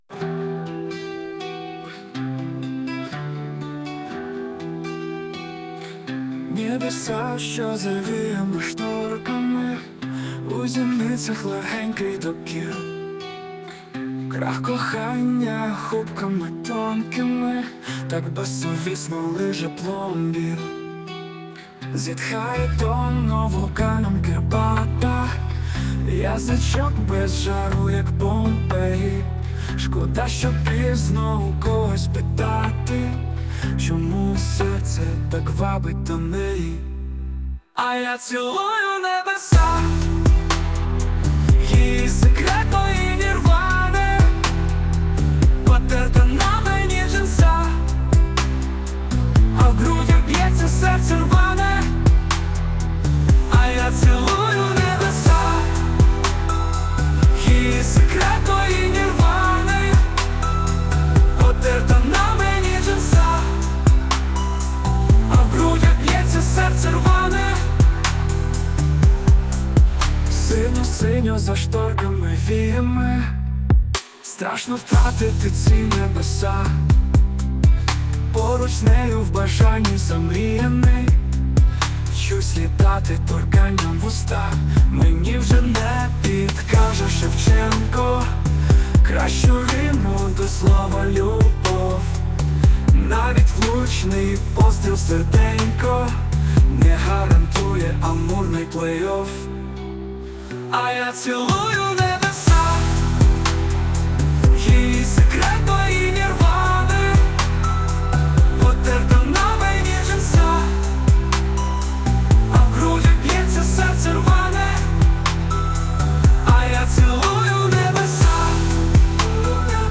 Музика та голос =SUNO
СТИЛЬОВІ ЖАНРИ: Ліричний
ВИД ТВОРУ: Пісня